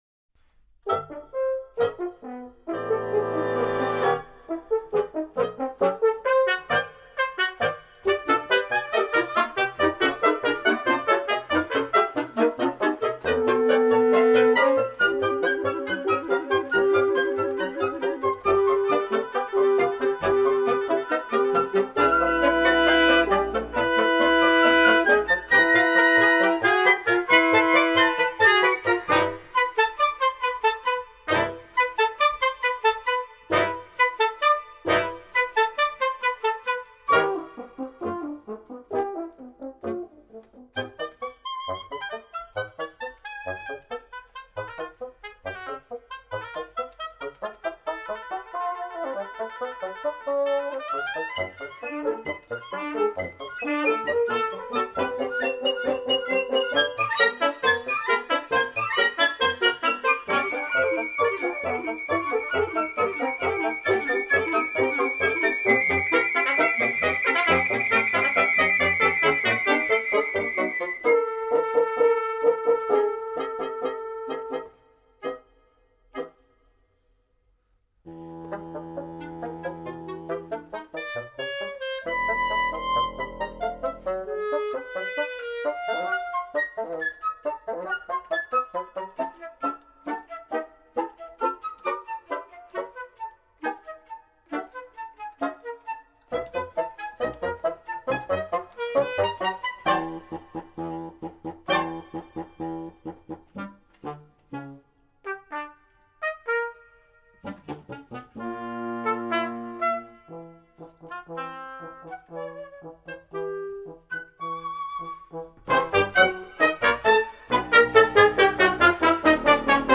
per 13 fiati